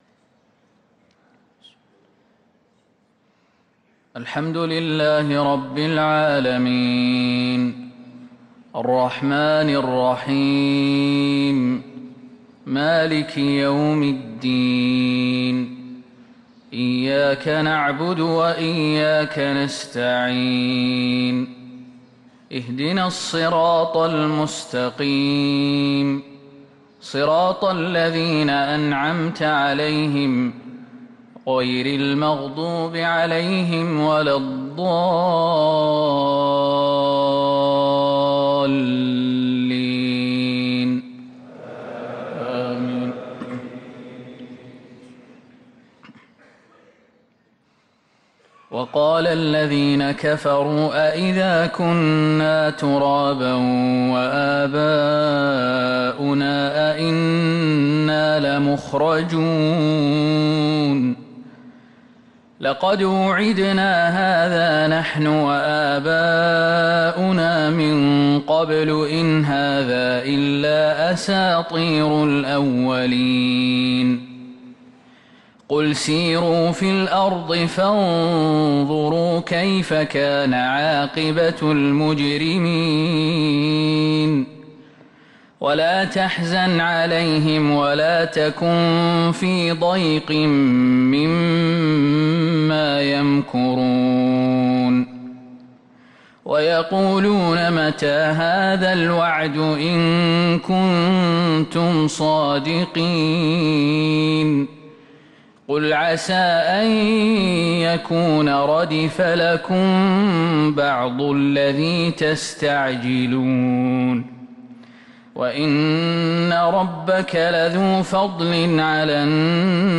صلاة الفجر للقارئ خالد المهنا 19 جمادي الأول 1444 هـ